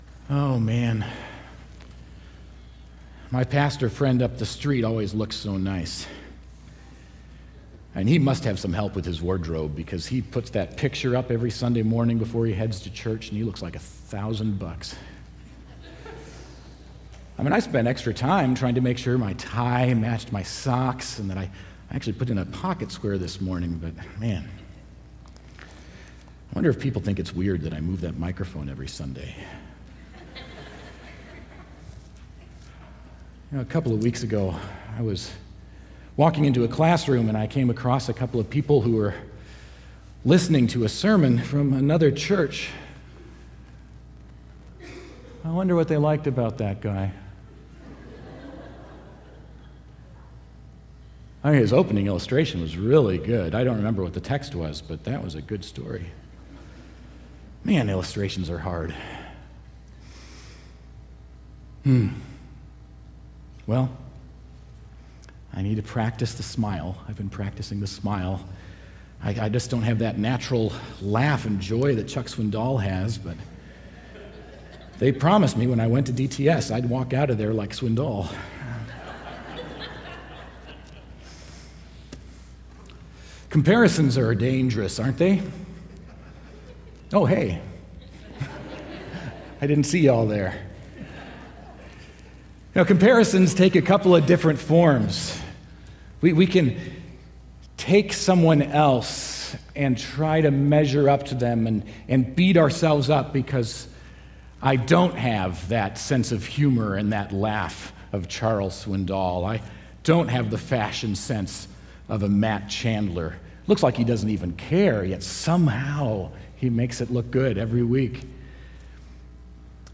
Sermon5.19.19.mp3